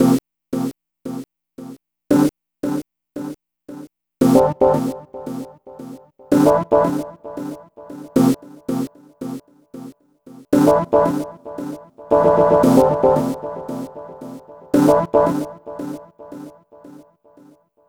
Ala Brzl 2 Rhodez-C.wav